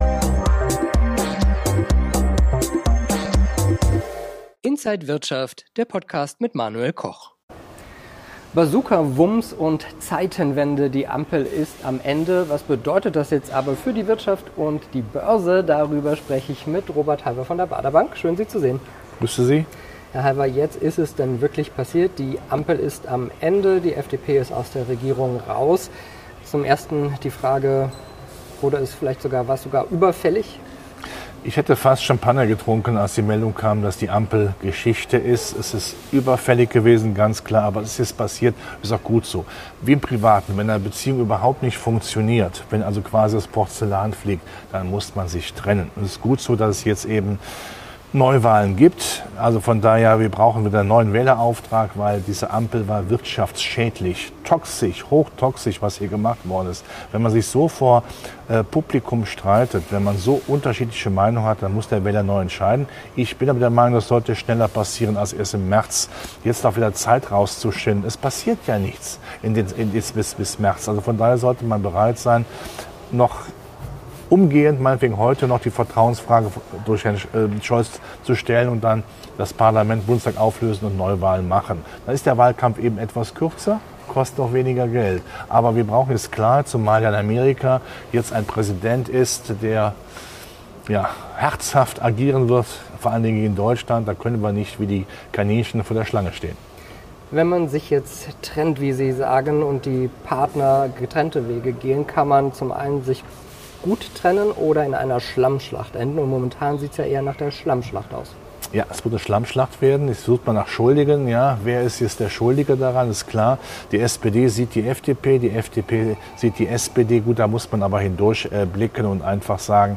Alle Details im Interview von